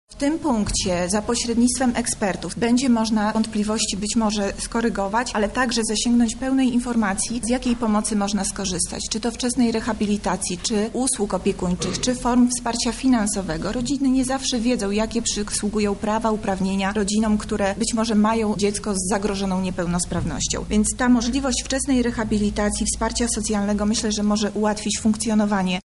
– tłumaczy Monika Lipińska, zastępca prezydenta Lublina